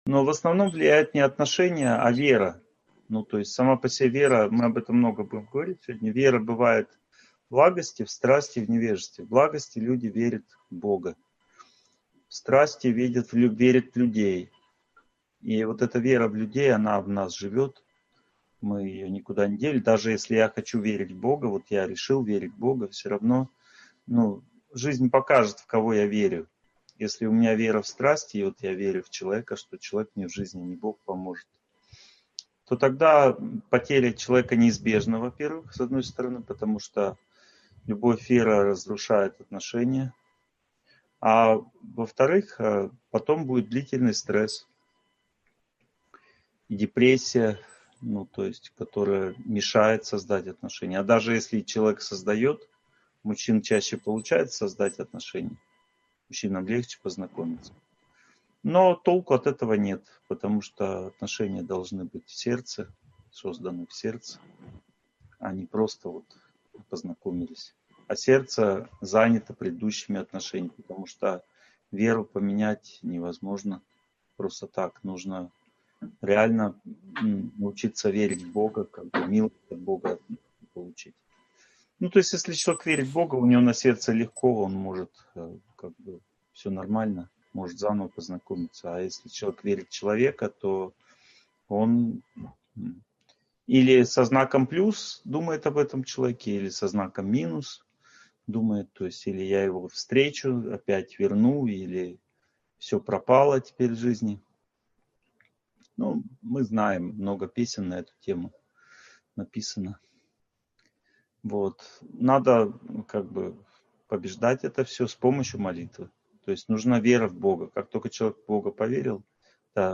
Одиночество и победа над ним (онлайн-семинар, 2020)